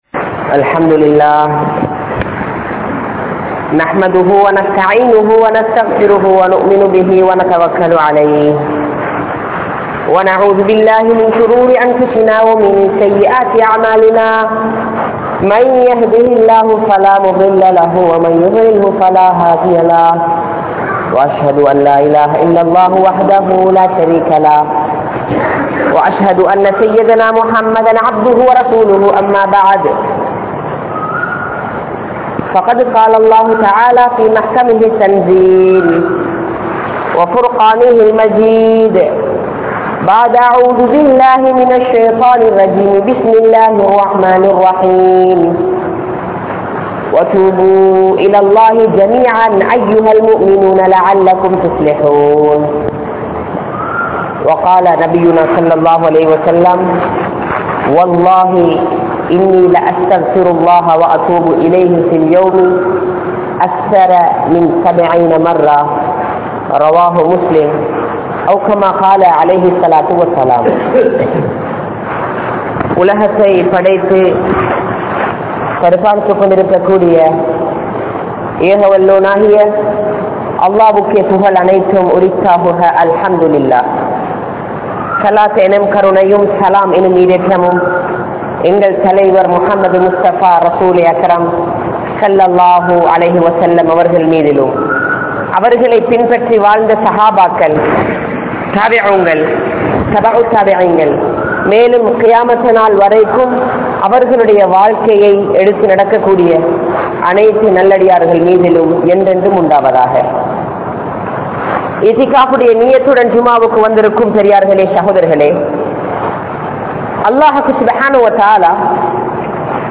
Isthigfar Seiungal (இஸ்திஃபார் செய்யுங்கள்) | Audio Bayans | All Ceylon Muslim Youth Community | Addalaichenai